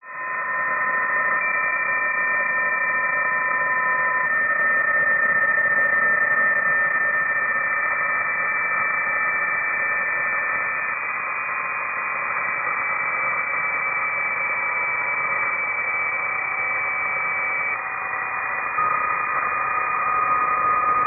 Here are the WAV files he generated with Doppler changing at
3, and